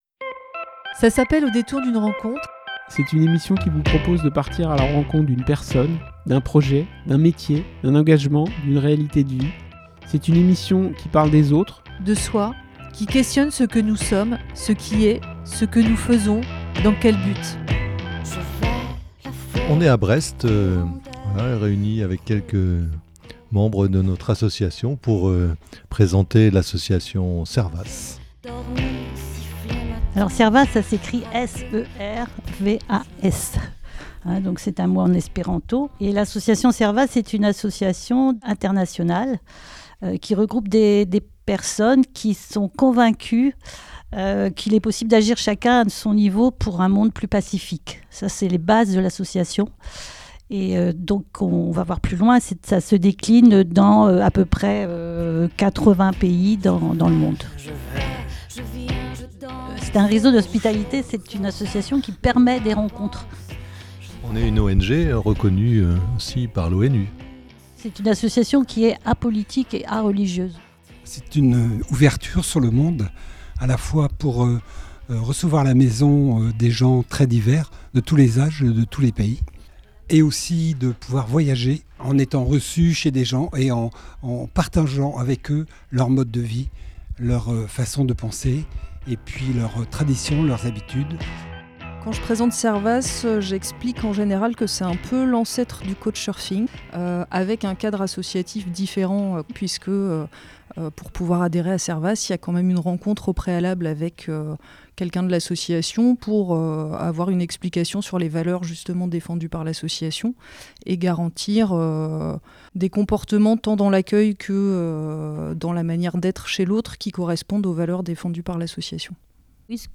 Cinq voix pour raconter des rencontres, pour raconter une aventure associative, celle de l'association Servas, qui depuis plus de 75 ans, travaille pour une cause plus que jamais d'actualité : renforcer la paix dans le monde.